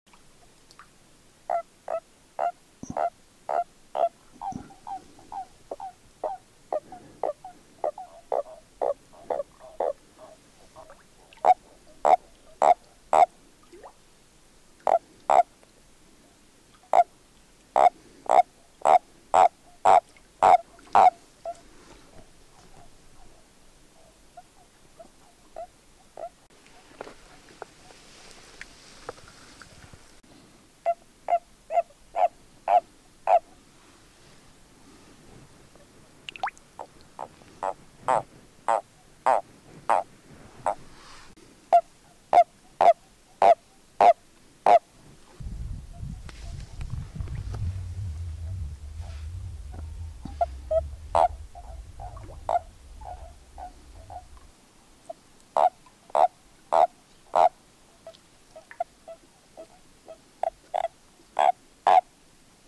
CriContestationMaleCrapaudEpineux.mp3